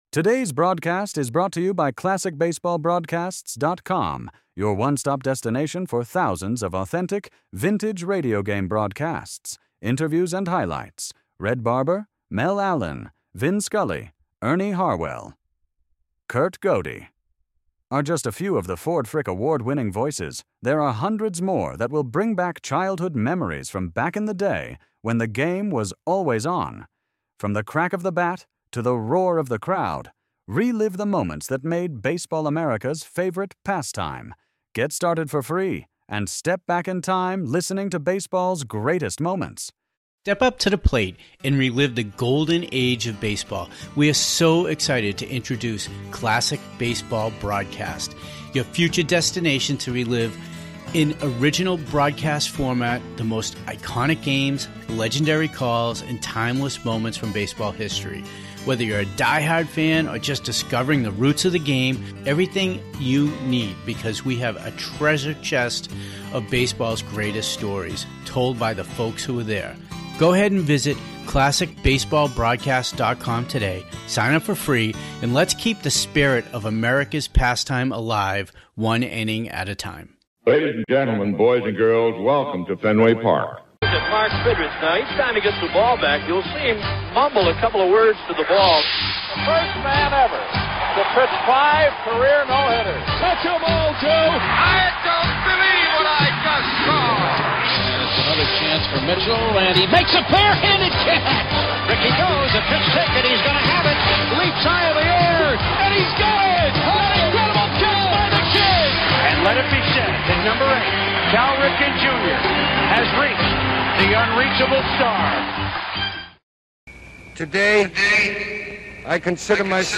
Ducky Medwick Interview - March 21